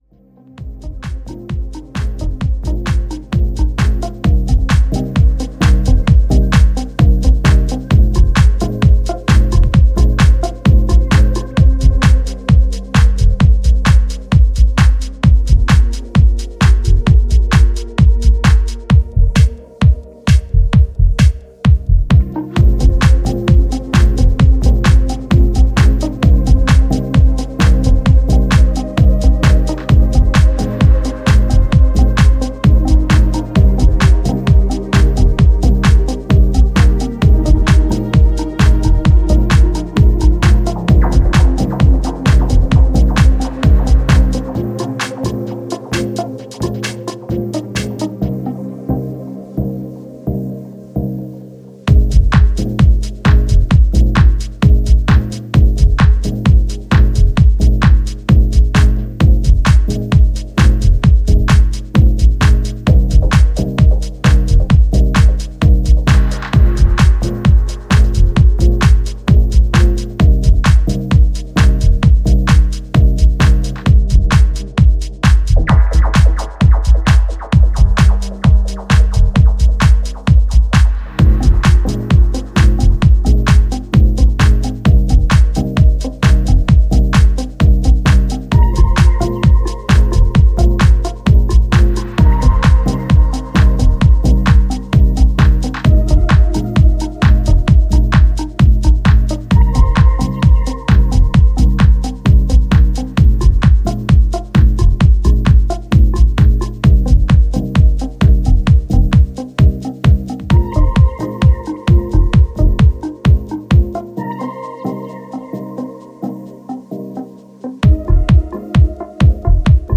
筋肉質なビートがフロアに映えるメロウチューン